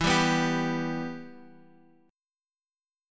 Listen to G6/E strummed